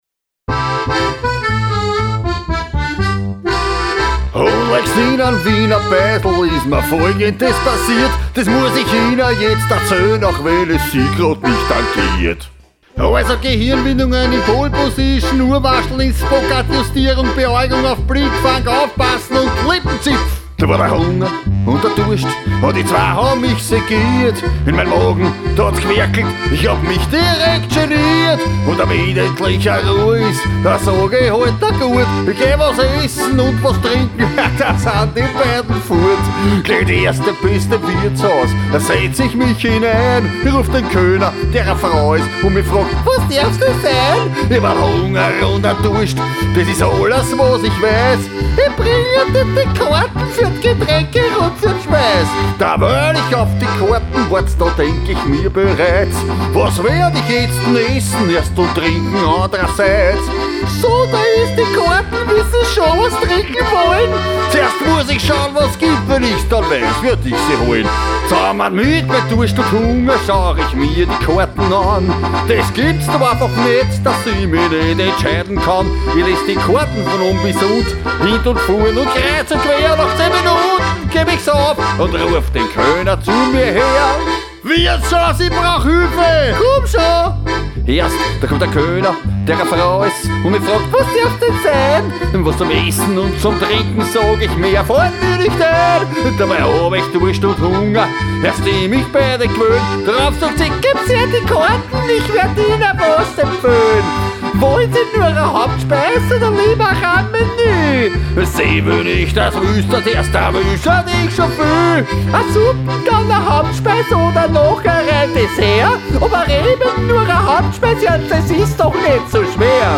Kabarett Version